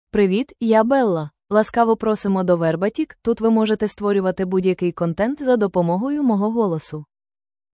Bella — Female Ukrainian AI voice
Bella is a female AI voice for Ukrainian (Ukraine).
Voice sample
Listen to Bella's female Ukrainian voice.
Bella delivers clear pronunciation with authentic Ukraine Ukrainian intonation, making your content sound professionally produced.